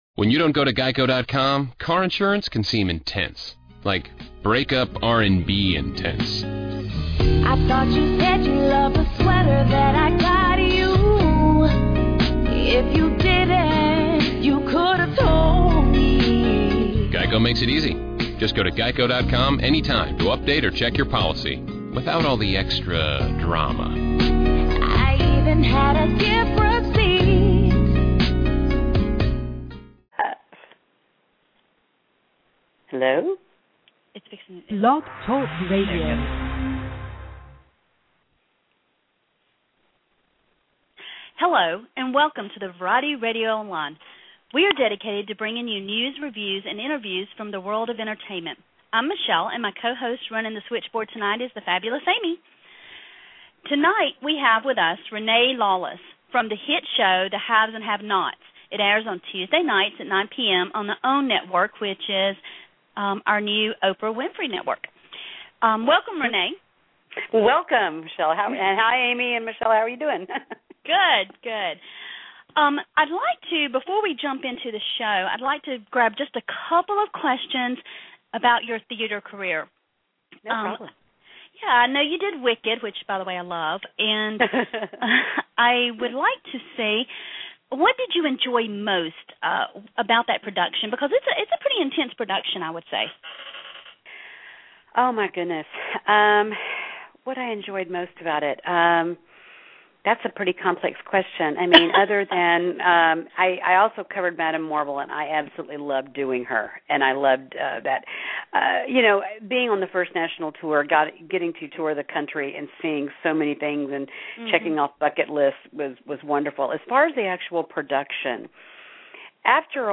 Renee Lawless - Interview